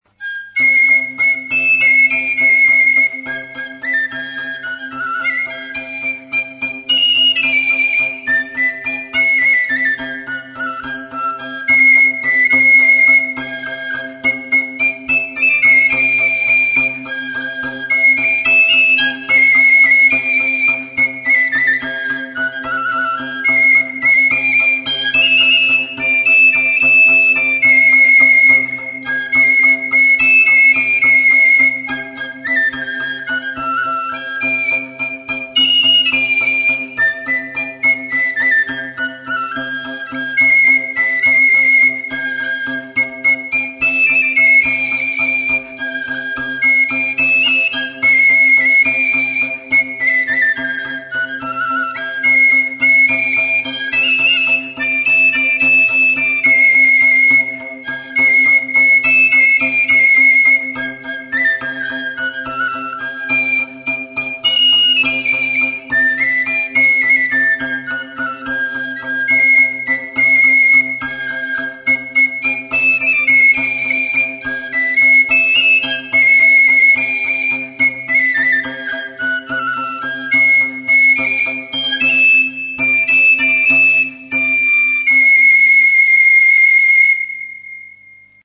DANBURIA; SOINUA; TTUN-TTUN
Stringed -> Beaten
Sei soka dituen zurezko erresonantzia kaxa luzea da.
Kolpatzeko makila, tinkatzeko giltza eta tablatxo batez egindako tonua aldatzeko zubia ditu.